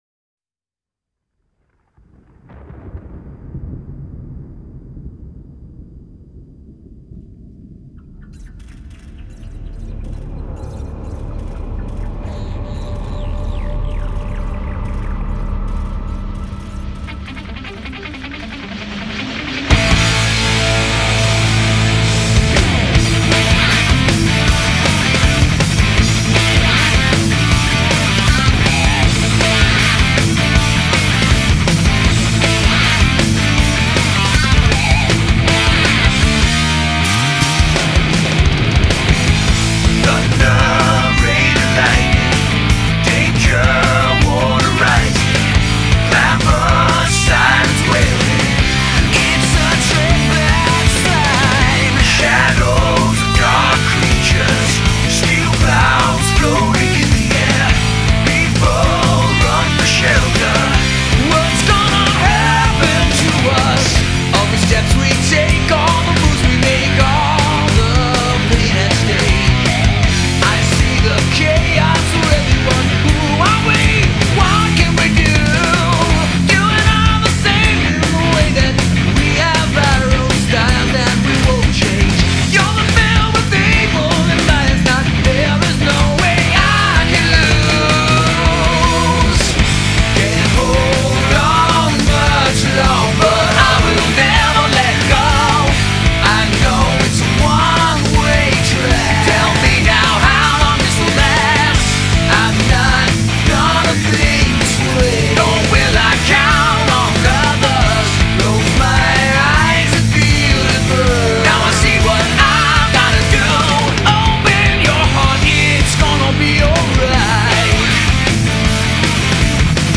содержащий полные версии вокальных треков